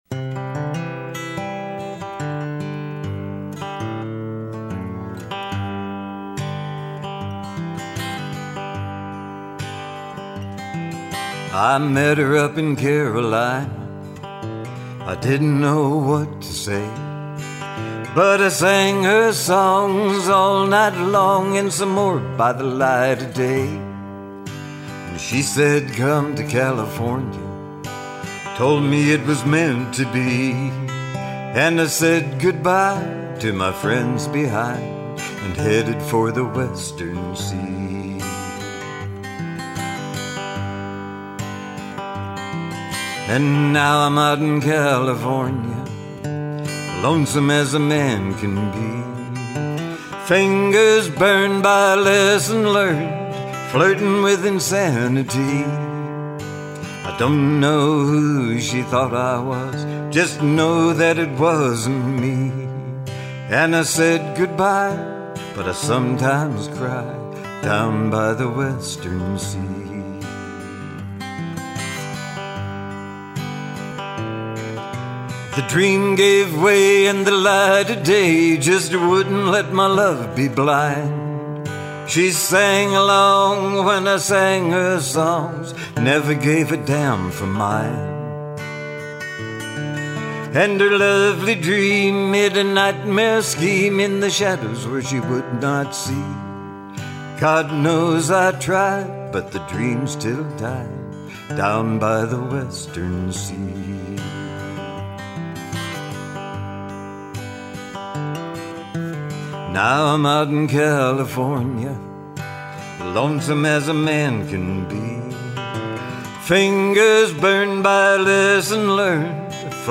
mostly just me and my guitars.